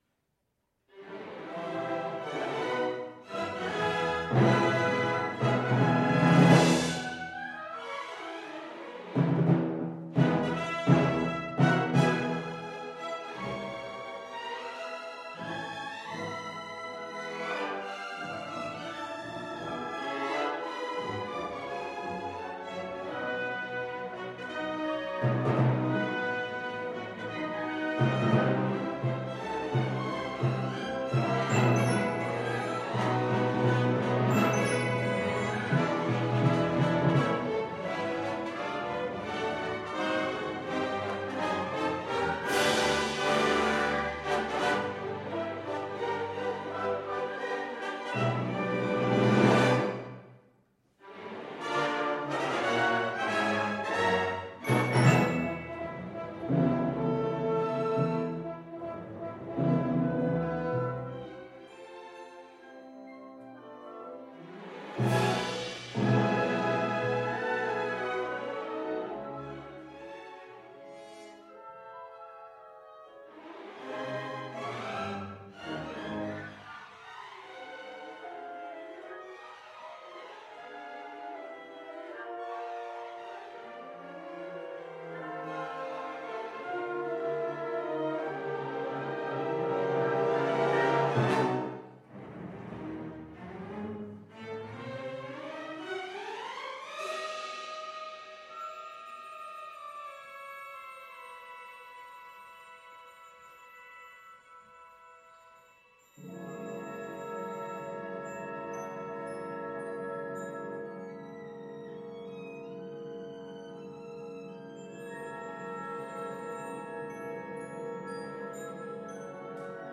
Orchestra
Style: Classical